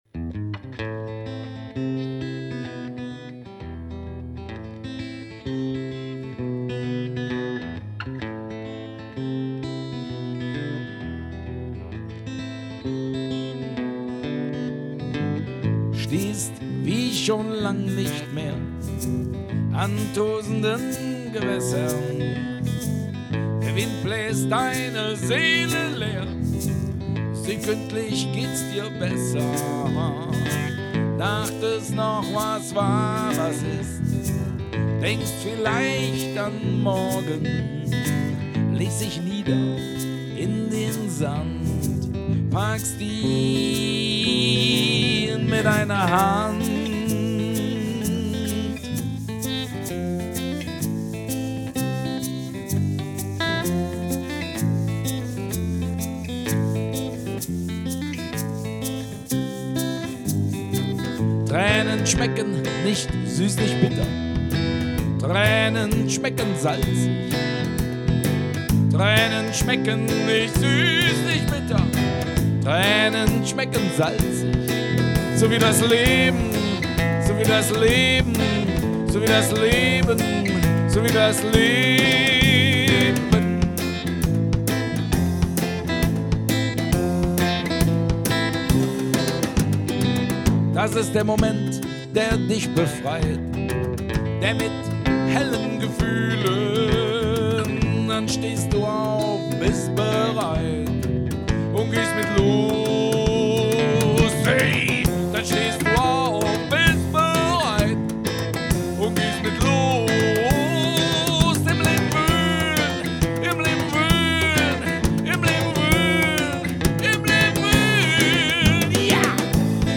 Unplugged Set - live aufgenommen im Studio